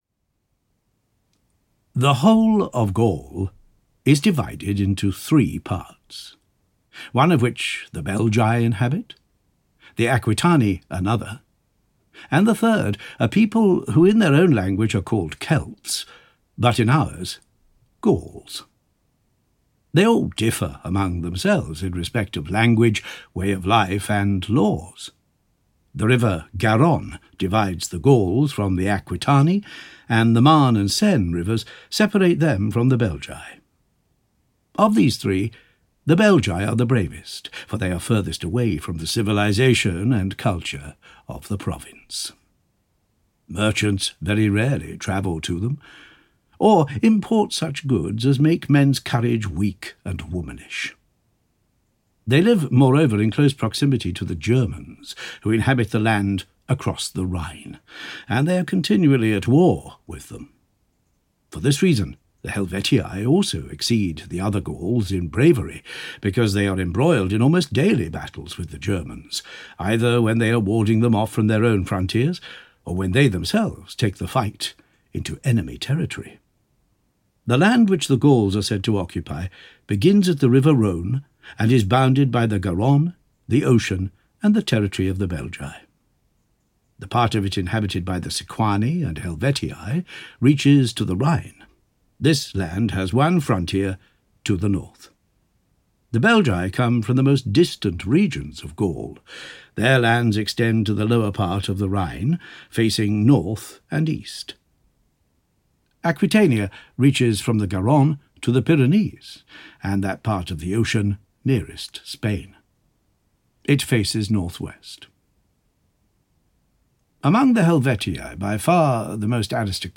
Audio knihaThe Gallic War
Ukázka z knihy